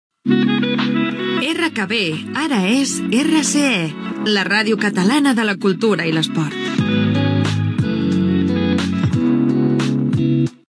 56cb7221db0fa97f0b90dd9a92efe7ddb8d586c1.mp3 Títol Radio Kanal Barcelona Emissora Radio Kanal Barcelona Titularitat Tercer sector Tercer sector Comercial Descripció Indicatiu avisant del canvi de nom de l'emissora de RKB a RCE.